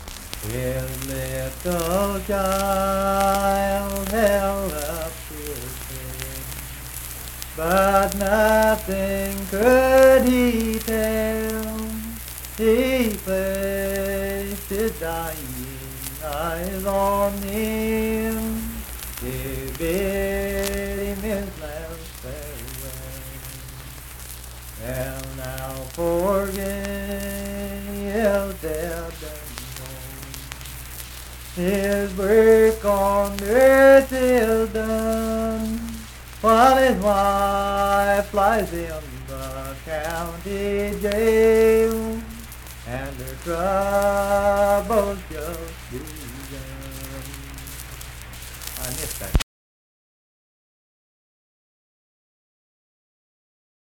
Unaccompanied vocal music performance
Voice (sung)
Braxton County (W. Va.), Sutton (W. Va.)